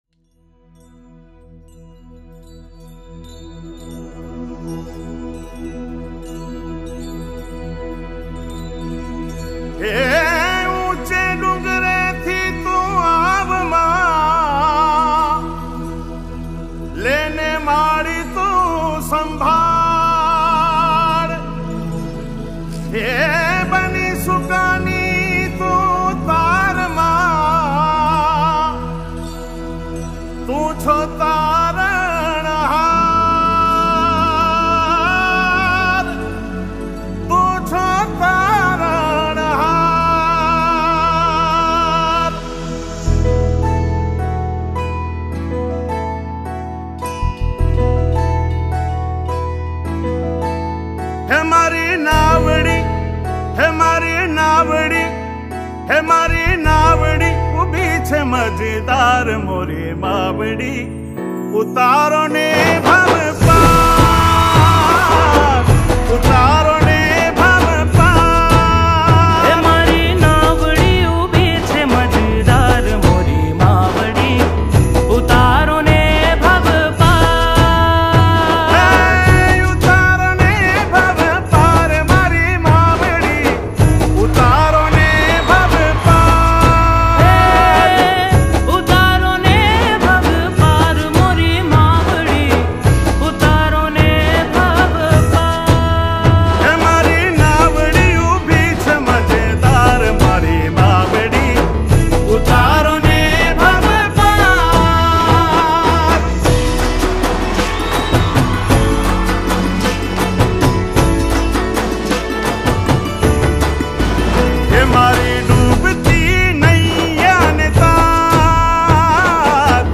Gujarati Bhakti Song